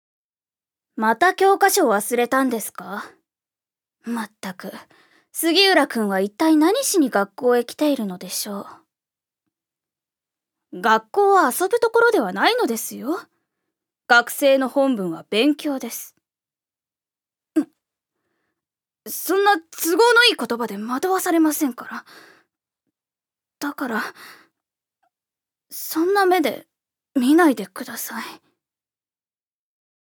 預かり：女性
セリフ２